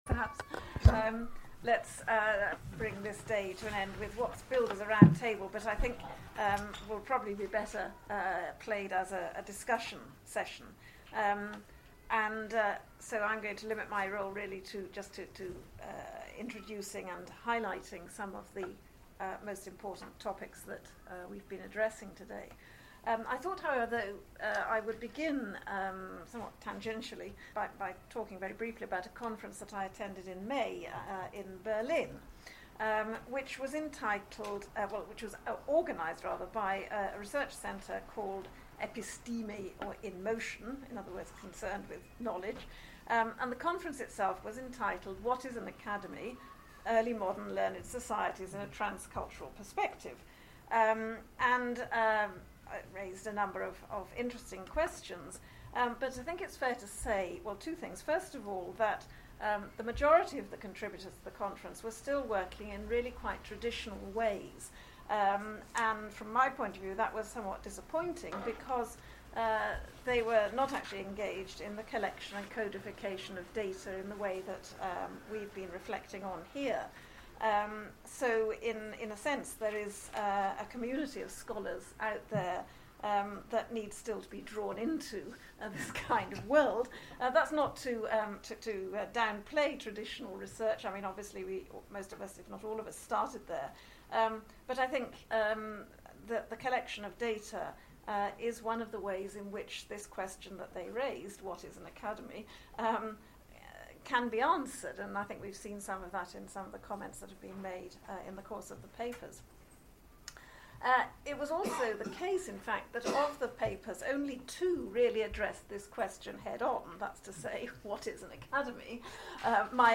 - Roundtable:Archiving the Academies of Early Modern Italy